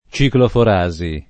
[ © iklofor #@ i ]